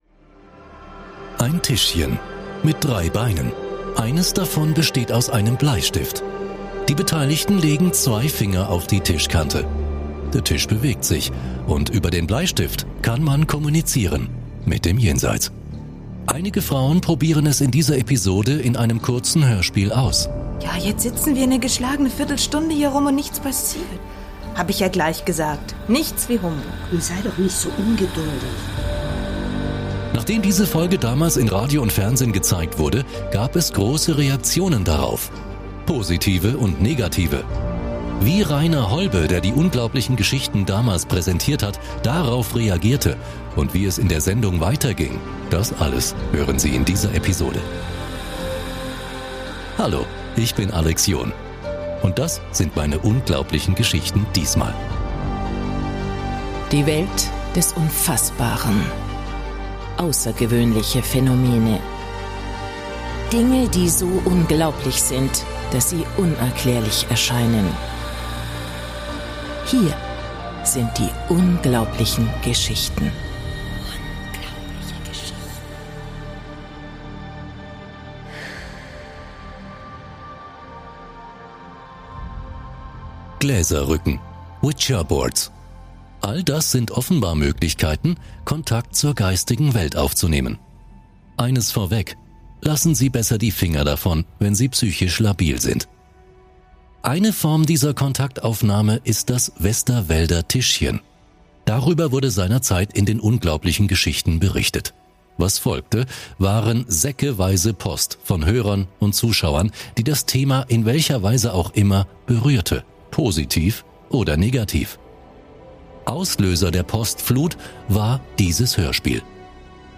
Ist aber ein Thema, um das es in der Sendung „Zukunft 2000“ geht, die wir in dieser Episode hin voller Länge hören. Über die Unglaublichen Geschichten: In den 1970er Jahren eroberte eine Sendung die Radiowellen, die bis heute für ihre fesselnden Geschichten und mysteriösen Ereignisse in Erinnerung bleibt: "Unglaubliche Geschichten". Diese Radiosendung, moderiert von Rainer Holbe, bot den Hörern eine Plattform, um in die Welt des Übersinnlichen und Unerklärlichen einzutauchen.